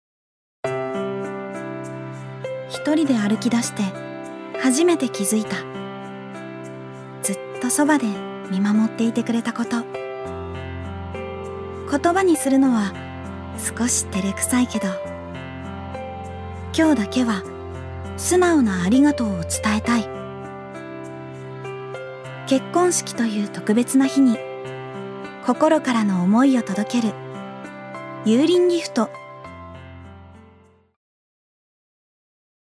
ジュニア：女性
ナレーション４